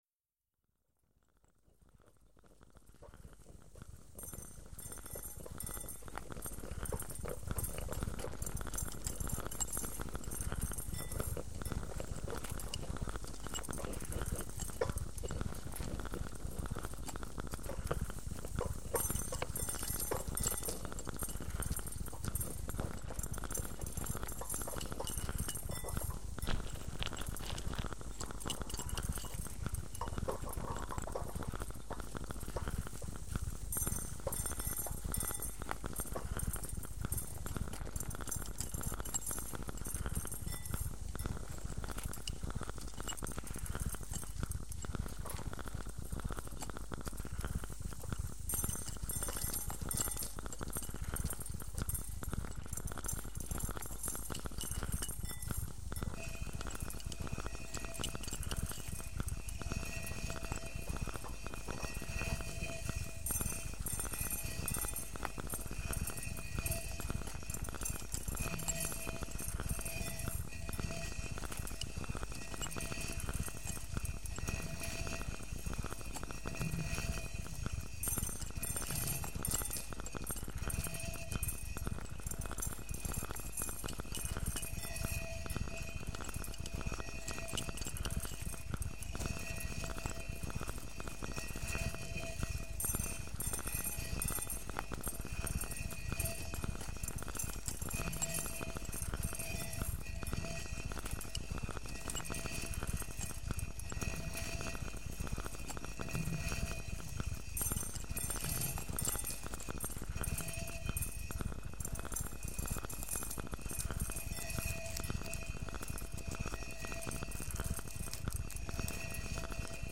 mélant musique concrète et instrument jouet.